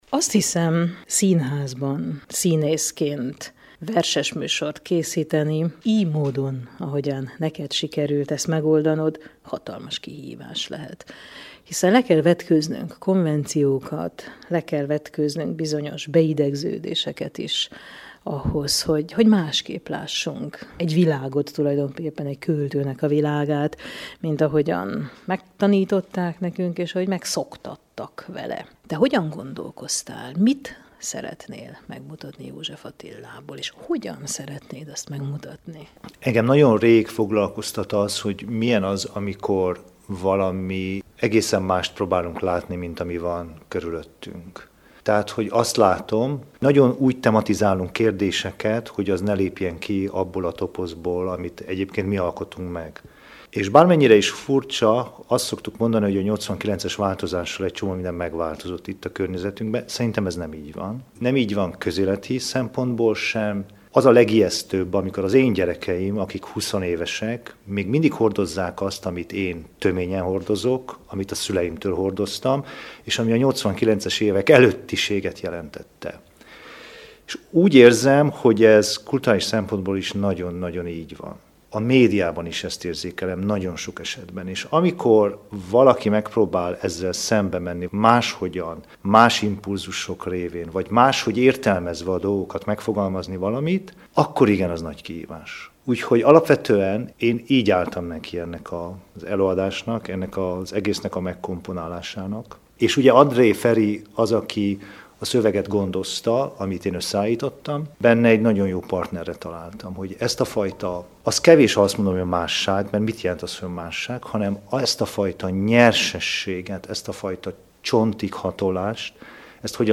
Úgy hangozzanak József Attila versei, ahogy mi is élünk - Beszélgetés